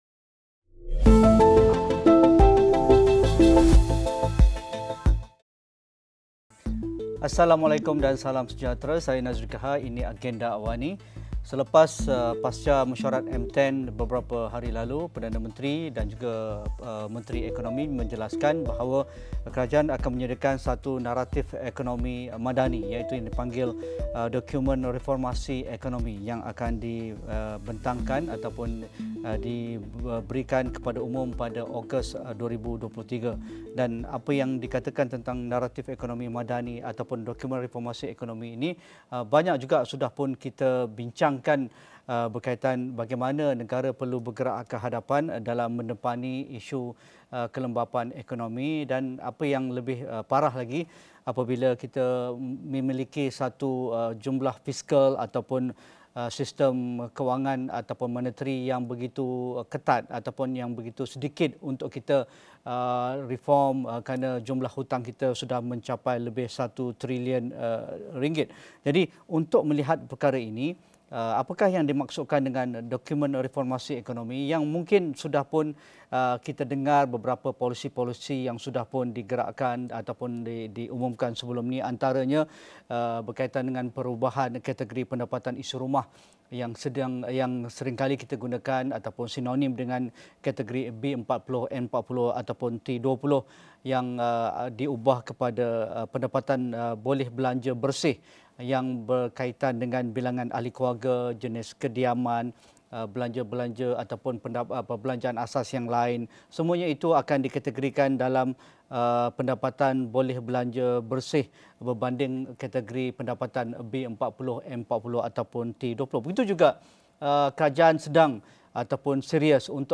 Diskusi 8.30 malam.